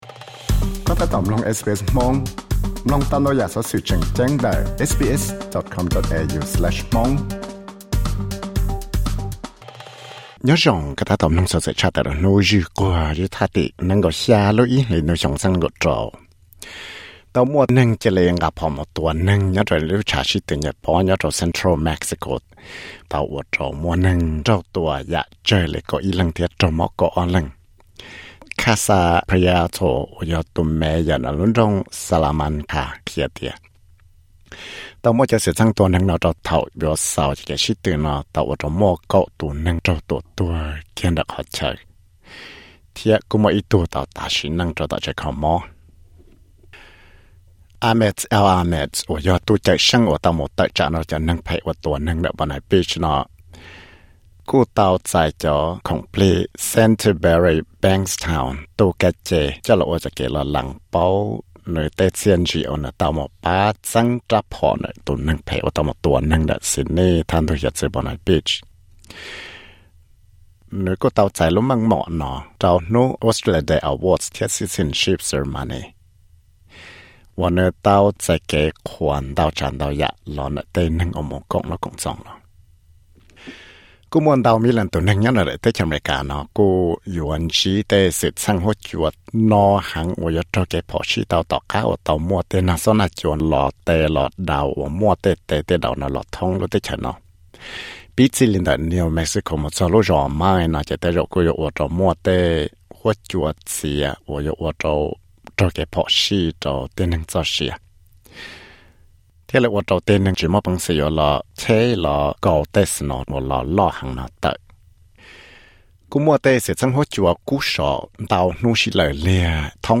Tuesday news: Huab cua kub sov thiab hav zoov kub hnyiab ntawm xeev Victoria